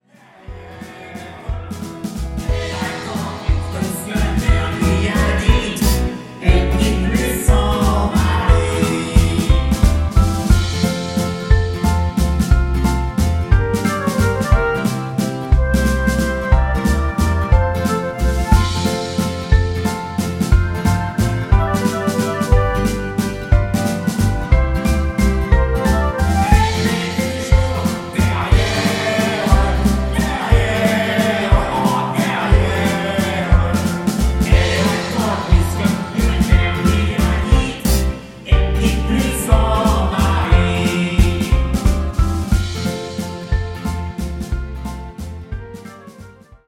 (avec choeurs)